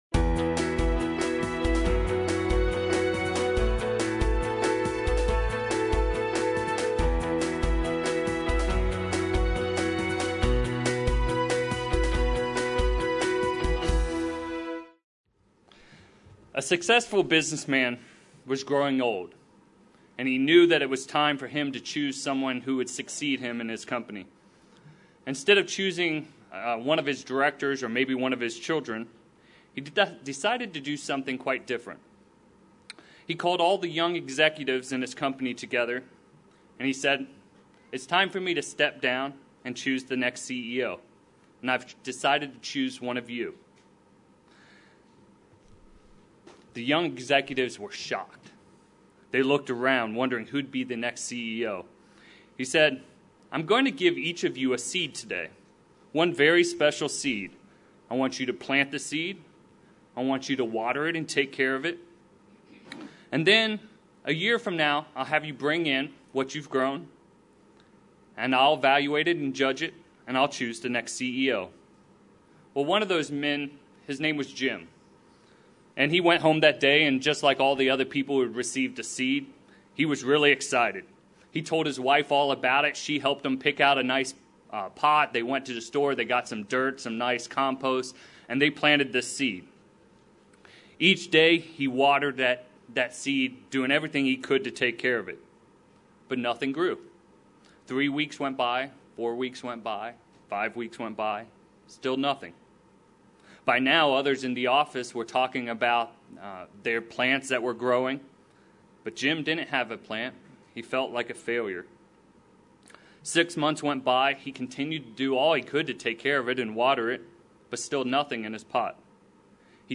This sermon focuses on being people of integrity.